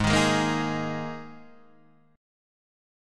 G# Suspended 2nd